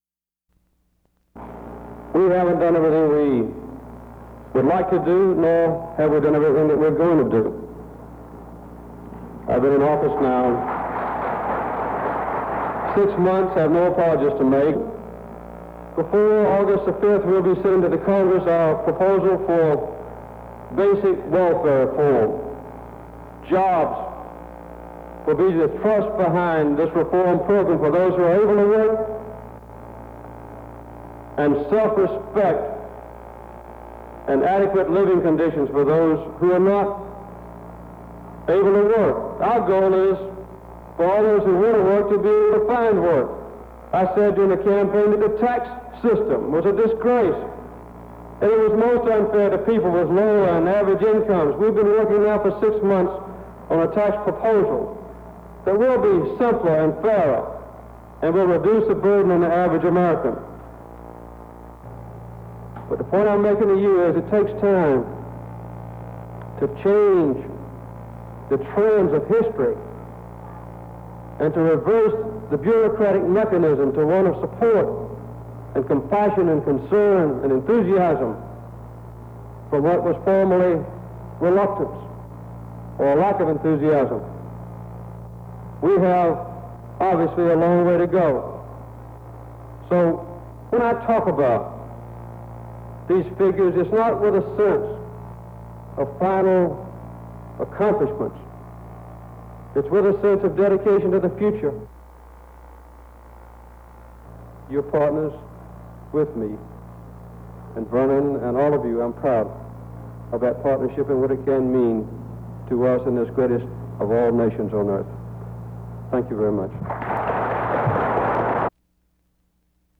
Jimmy Carter tells the national convention of the Urban League about his proposals for welfare and jobs programs